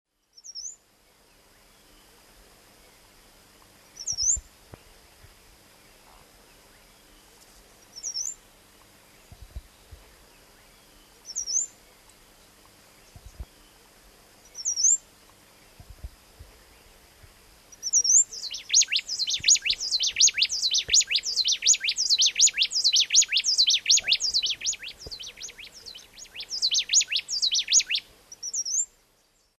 (PLAIN WREN)